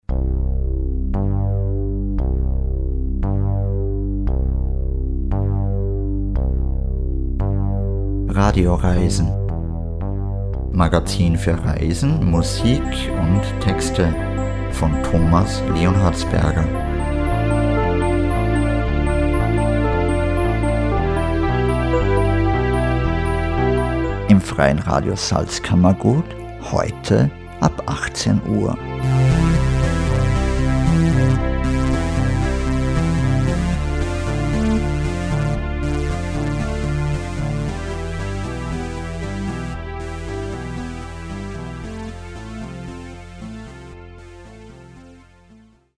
Sendungstrailer
FRS-TRAILER-RADIOREISEN-HEUTE-2-DONNERSTAG-18-UHR.mp3